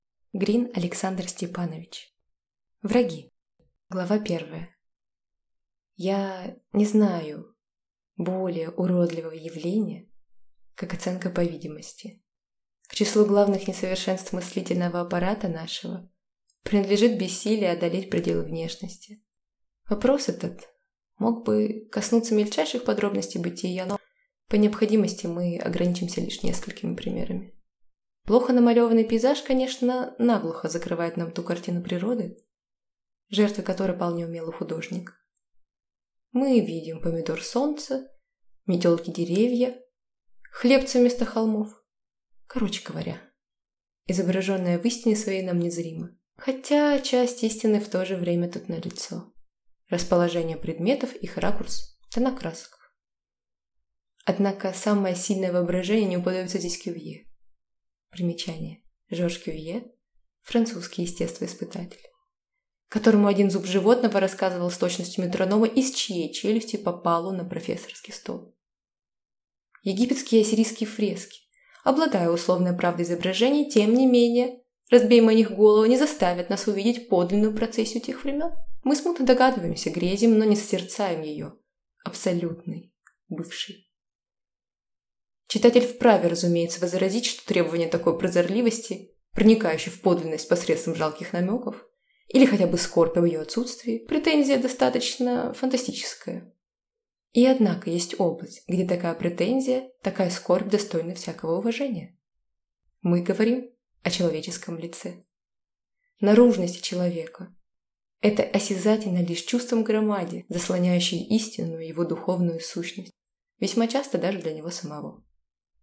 Аудиокнига Враги | Библиотека аудиокниг